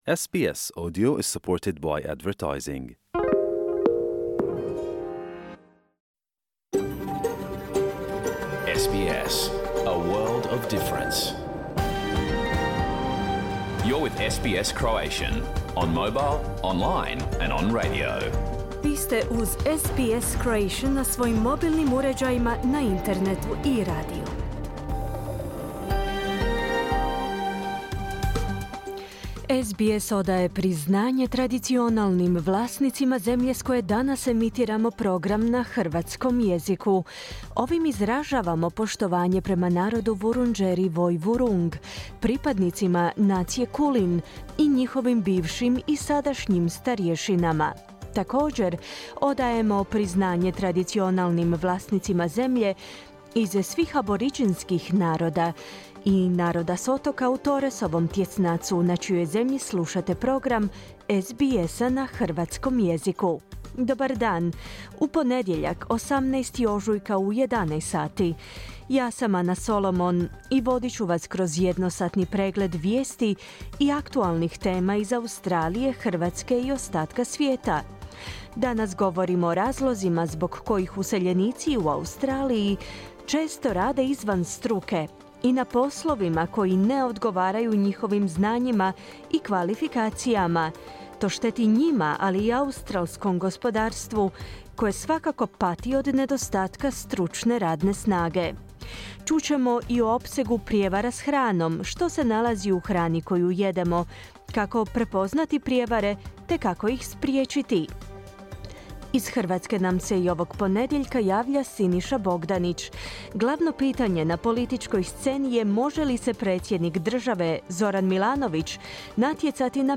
Pregled vijesti i aktualnosti iz Australije, Hrvatske i ostatka svijeta. Program je emitiran uživo u ponedjeljak,18. ožujka 2024., u 11 sati, po istočnoaustralskom vremenu.